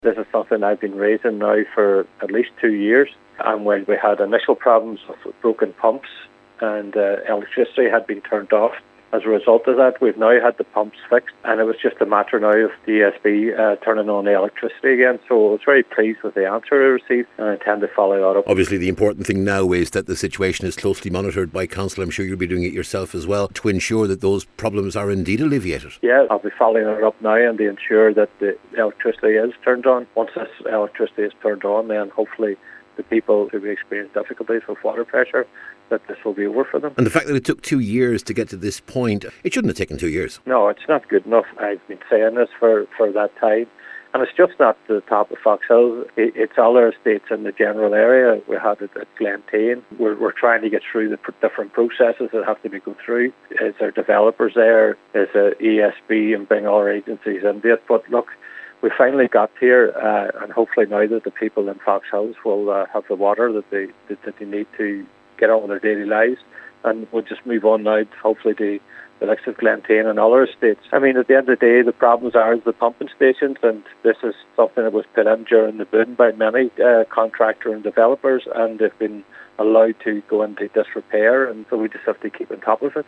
Cllr McMonagle says he’ll be monitoring the situation over the coming days, and after two years, it’s long past time that this problem should be resolved: